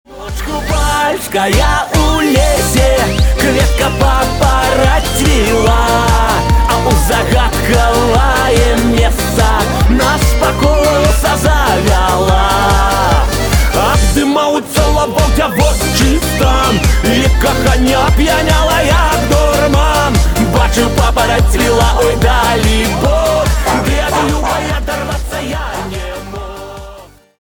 на белорусском веселые